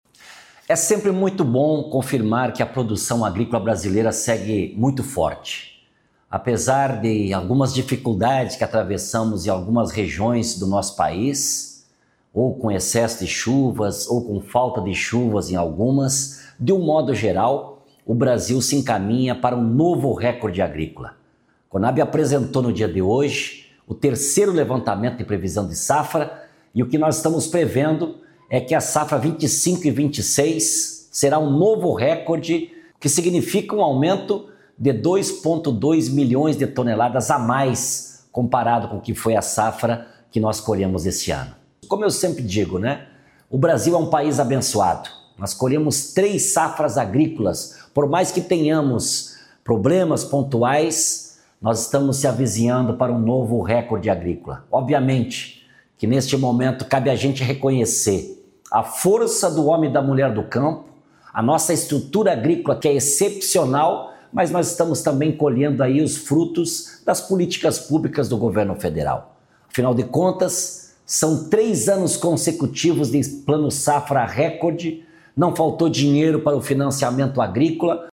Íntegra do anunciou pelo ministro do Desenvolvimento, Indústria, Comércio e Serviços, Geraldo Alckmin, do lançamento da plataforma inédita para consulta de investimentos estrangeiros diretos-IED, no Brasil e no mundo. o InvestVis, nesta quarta-feira (16), em Brasília.